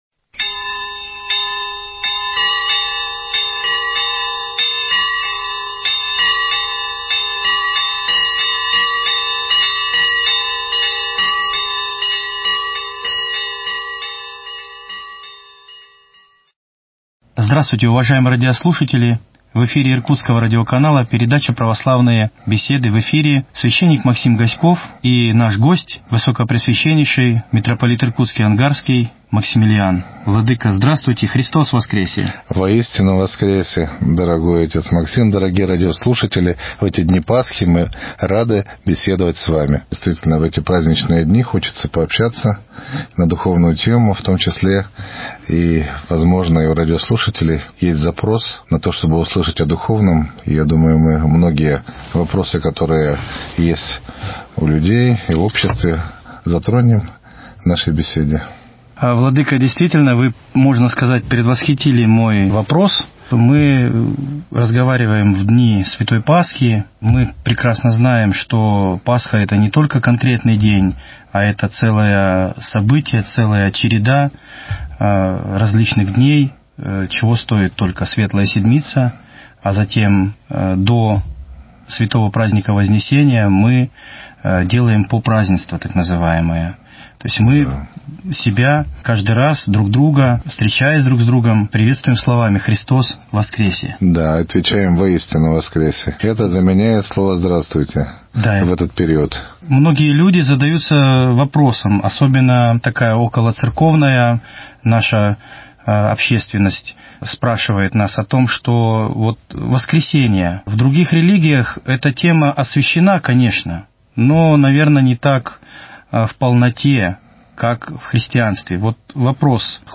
беседует с Митрополитом Иркутским и Ангарским Максимилианом